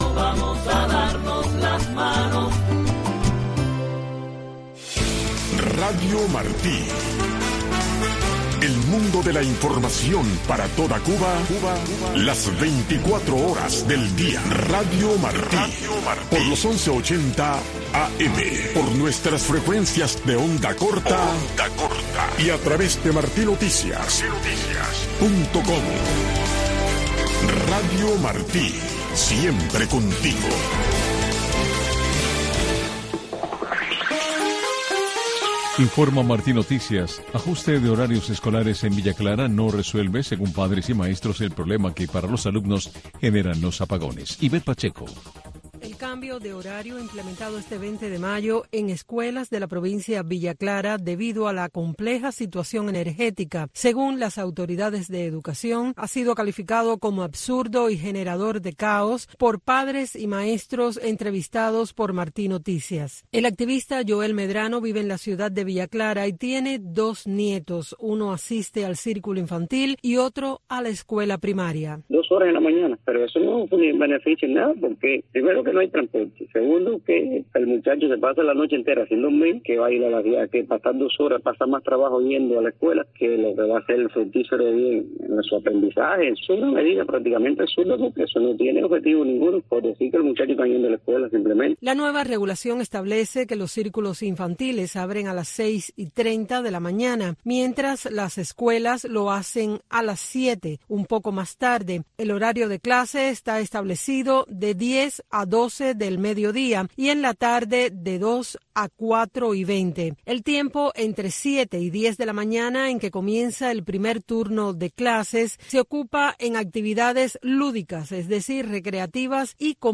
Un espacio donde se respeta la libertad de expresión de los panelistas y estructurado para que el oyente llegue a su propia conclusión.